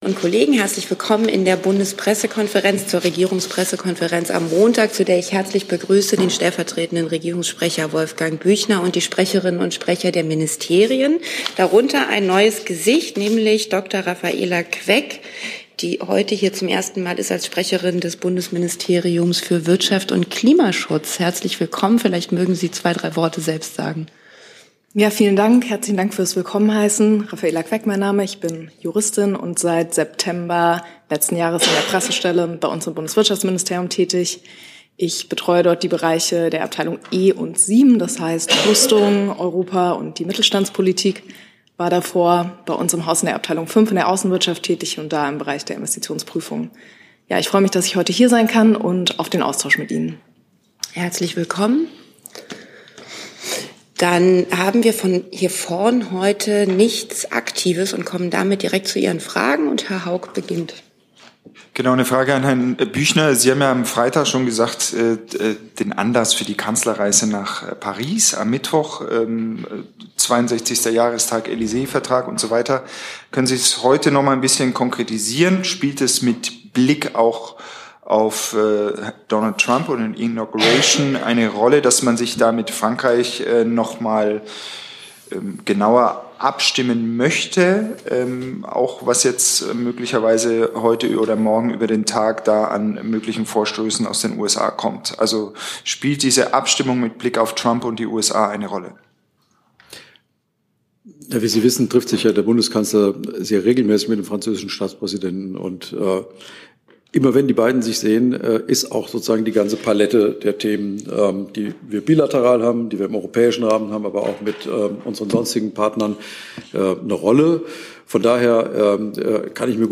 1 RegPK - 20. Januar 2025 - Trump-Administration 55:19 Play Pause 22h ago 55:19 Play Pause Nghe Sau Nghe Sau Danh sách Thích Đã thích 55:19 Bundesregierung für Desinteressierte Regierungspressekonferenz in der BPK vom 20. Januar 2025